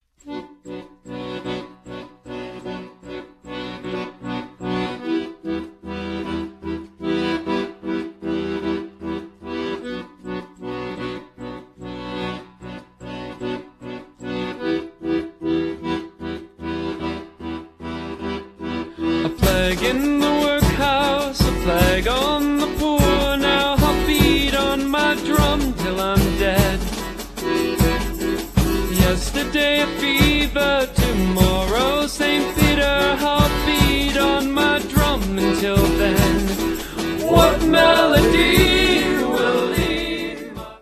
flugel horn
accordion
french horn